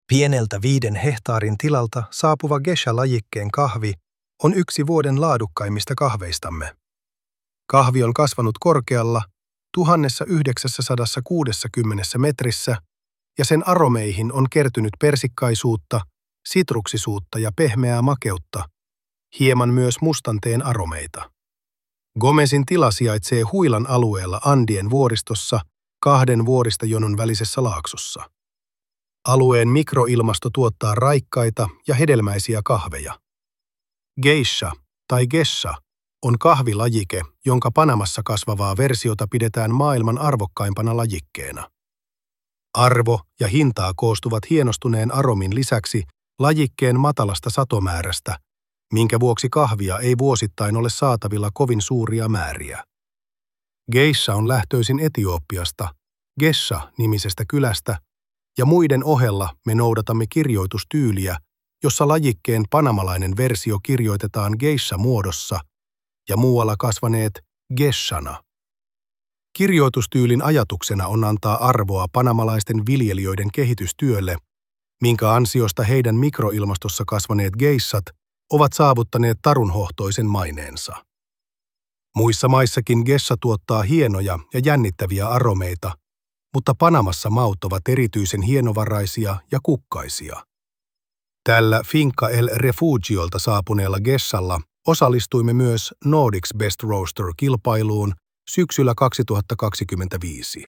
Tarinan äänitiedosto on luotu tekoälyllä.